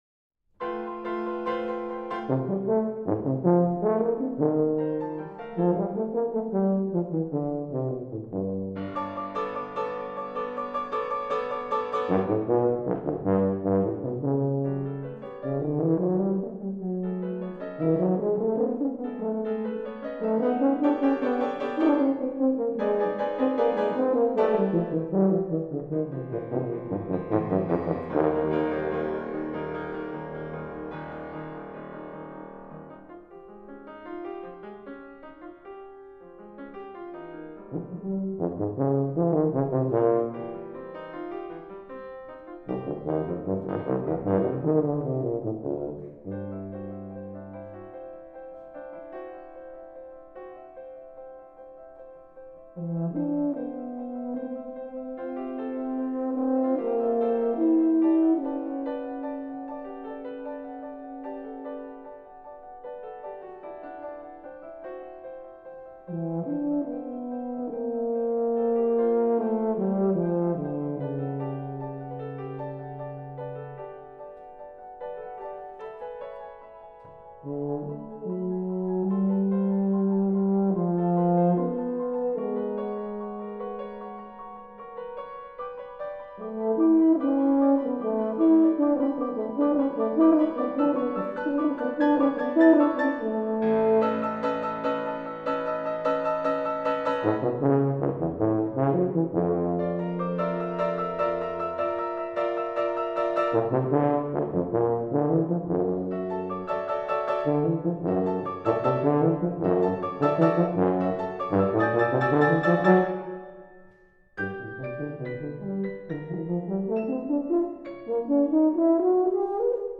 For Tuba Solo